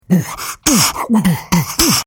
03_rap.mp3